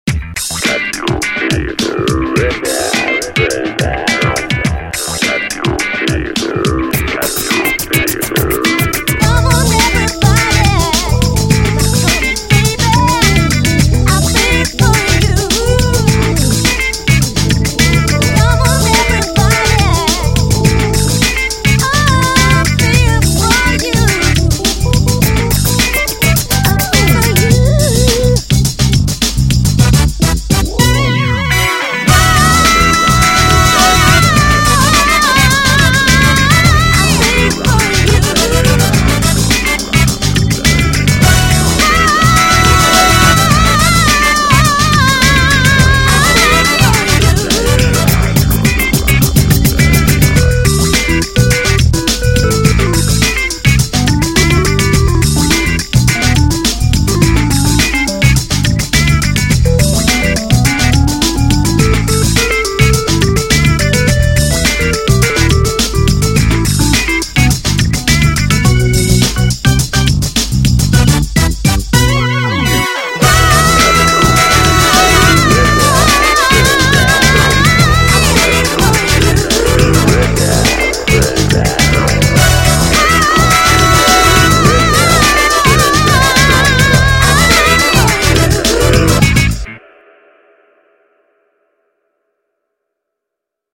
New Yorker Pop